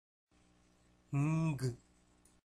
Nasals (Mellinam – Weak Sounds)
 = ng-g